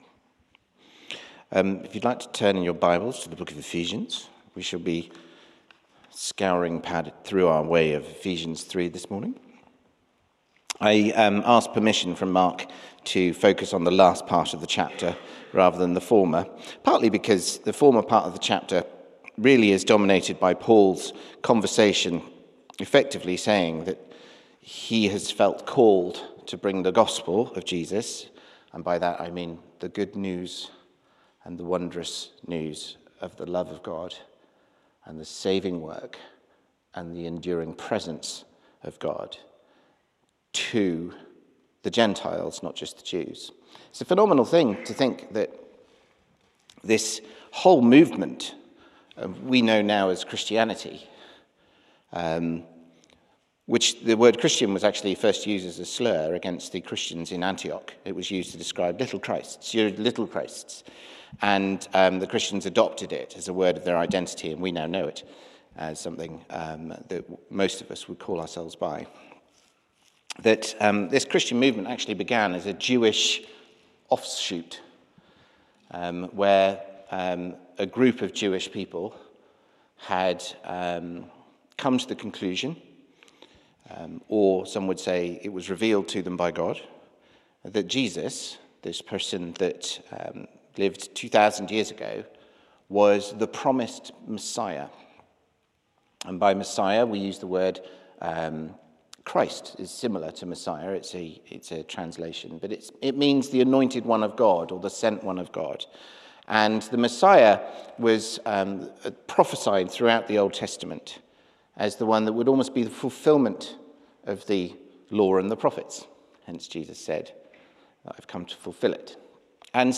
Sermon - Ephesians 3